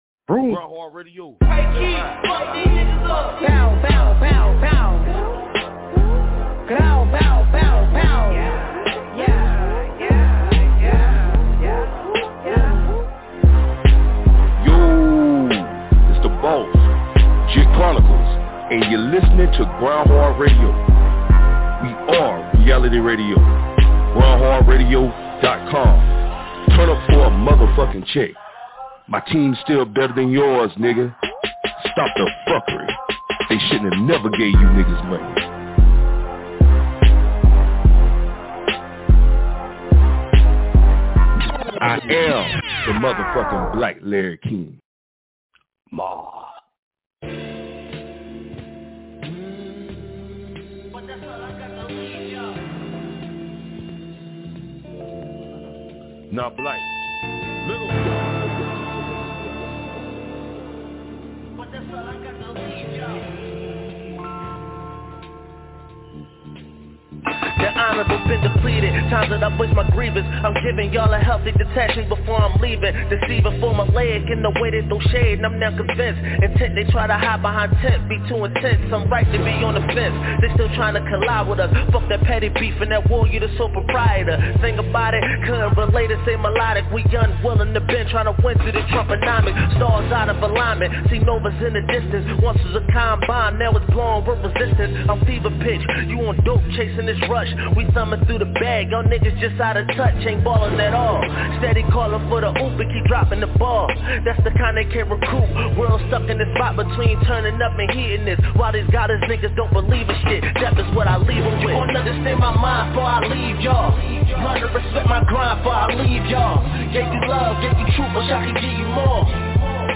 open-mic-nite-on-grindhard-radio.mp3